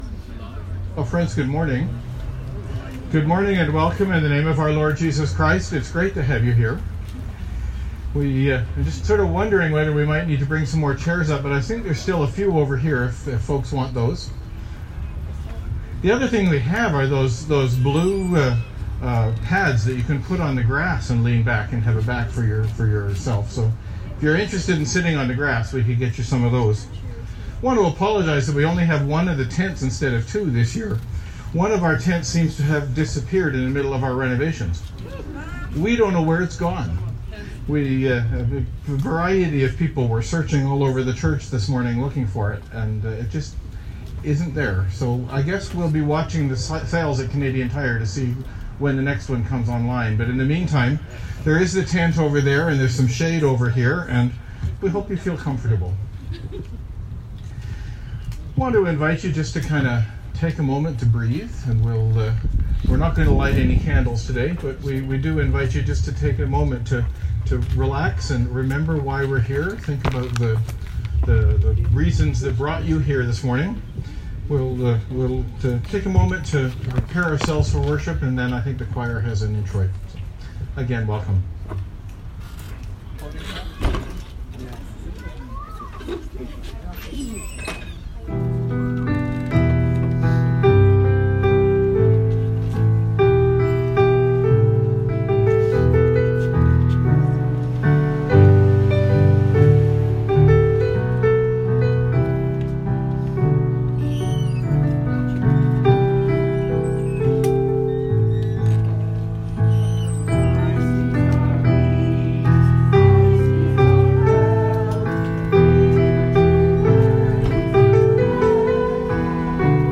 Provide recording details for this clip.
June 17, 2018Outdoor Service & BBQCelebration of Holy Communion